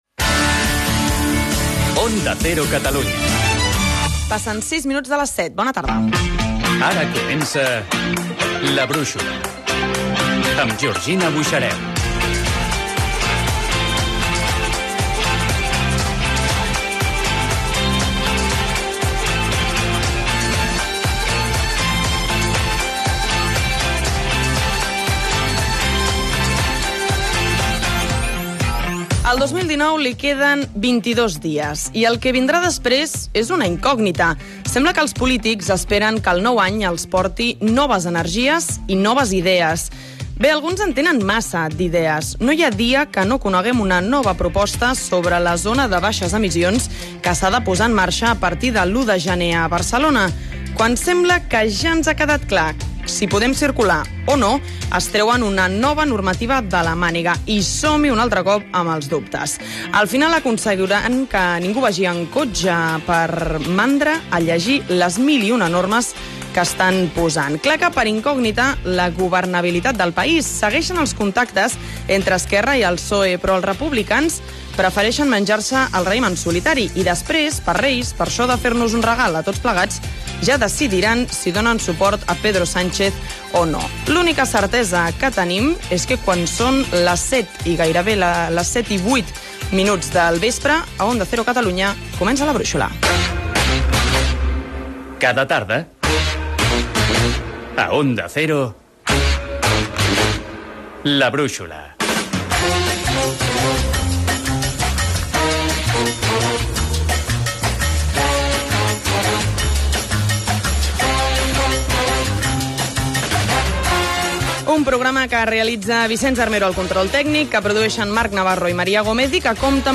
Indicatiu de l'emissora, hora, careta del programa, editorial amb l'actualitat del dia, indicatiu del programa, equip, sumari de continguts, telèfon de participació
Info-entreteniment